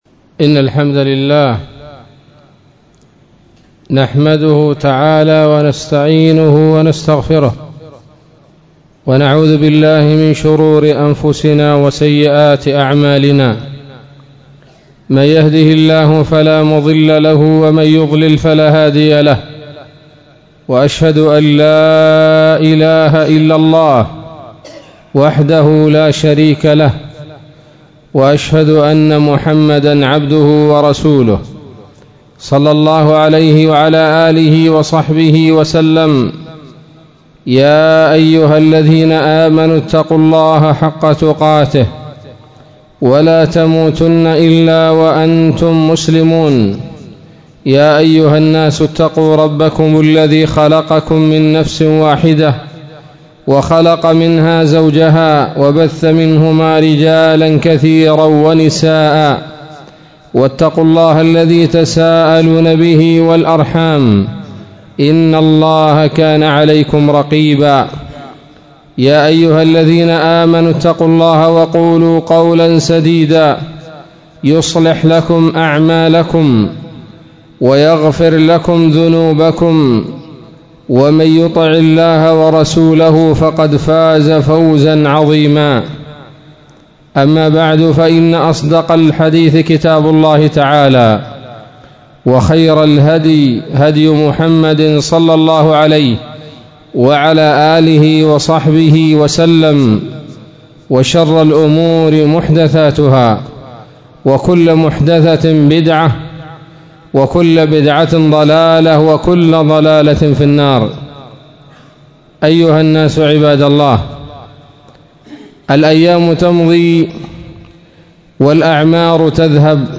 خطبة جمعة: (( وداع العام )) 2 من شهر محرم 1439هـ